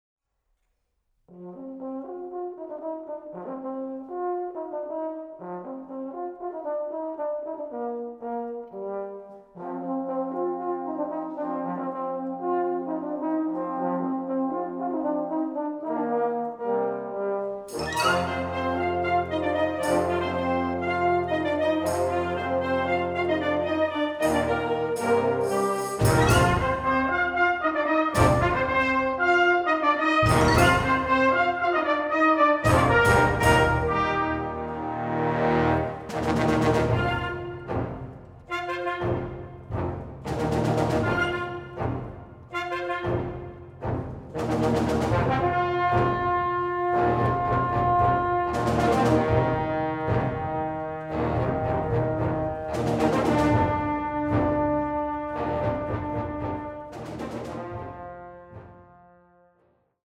Musik für sinfonisches Blasorchester
Sinfonisches Blasorchester